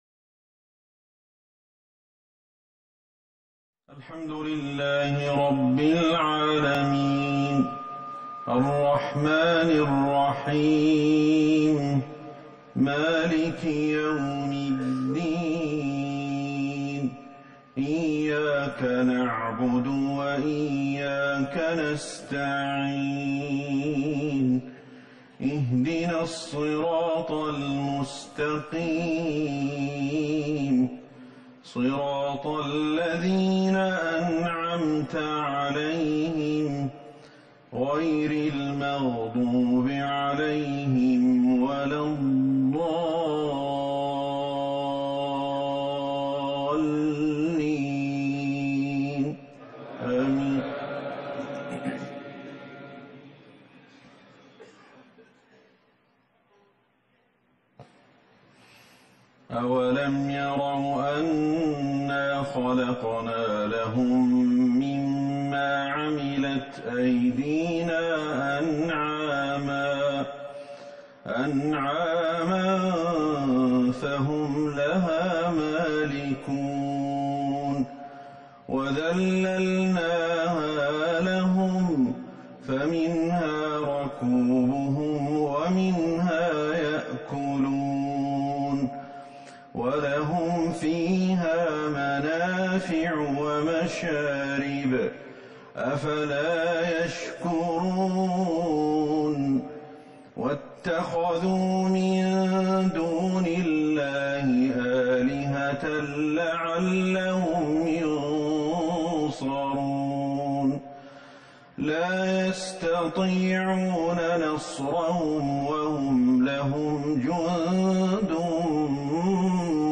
صلاة المغرب ١٤ جمادي الاخره ١٤٤١هـ سورة يس Maghrib prayer 8-2-2020 from Surah Yasin > 1441 🕌 > الفروض - تلاوات الحرمين